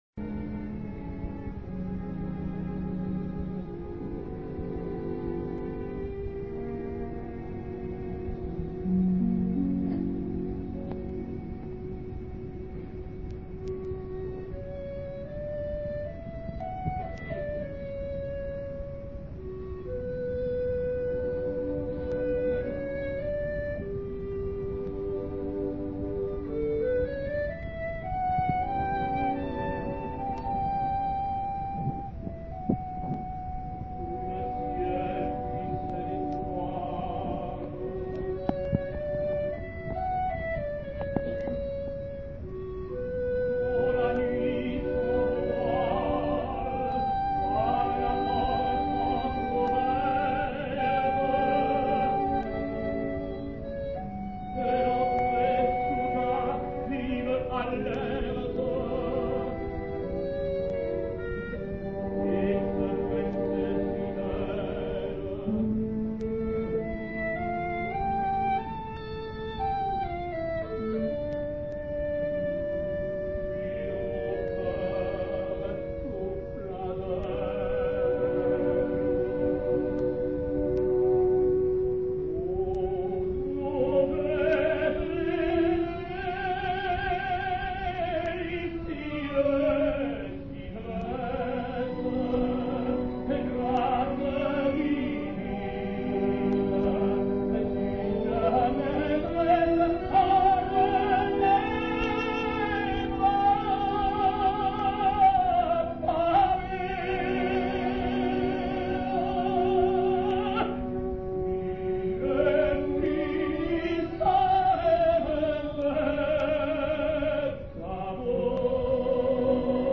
Le ciel luisait d'étoiles, with encore in Italian